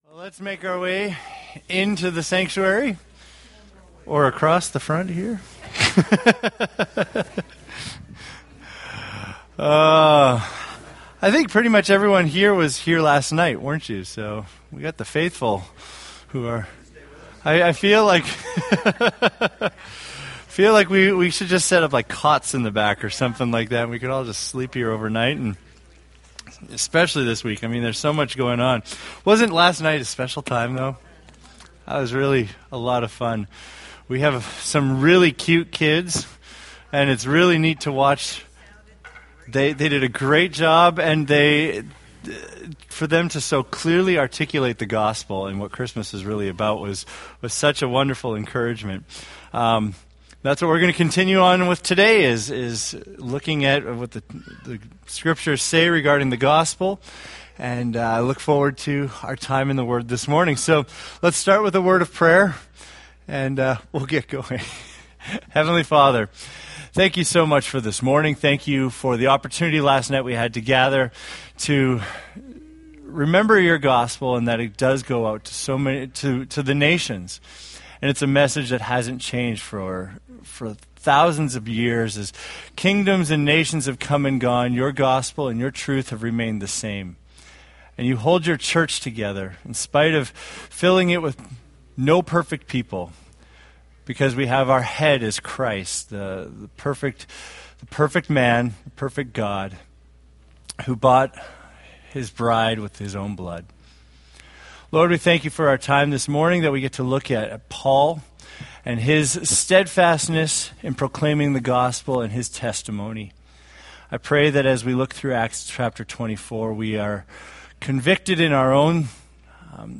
Date: Dec 7, 2014 Series: Acts Grouping: Sunday School (Adult) More: Download MP3